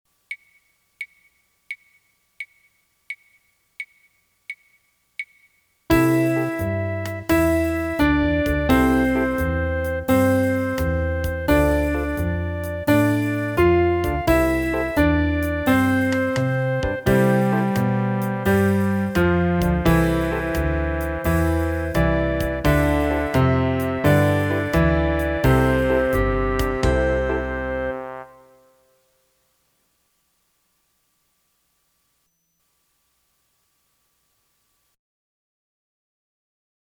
Voicing: Piano/CD